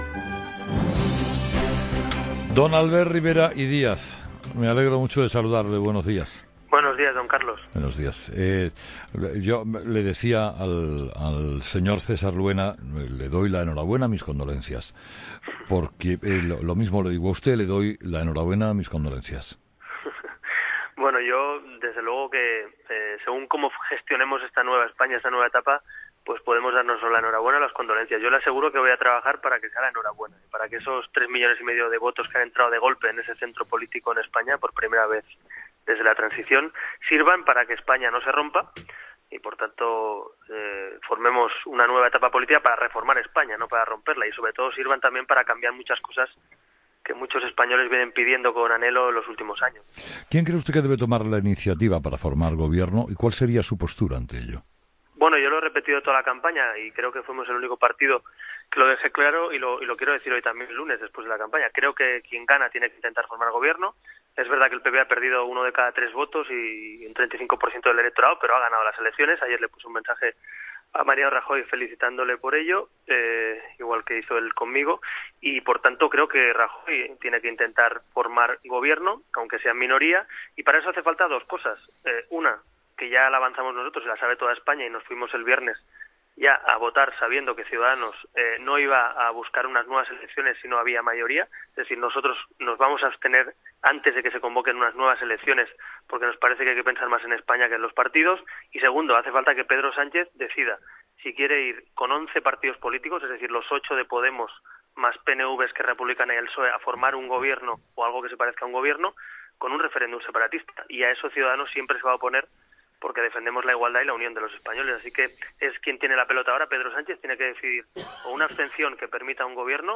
Entrevista con Albert Rivera - COPE
Entrevistado: "Albert Rivera"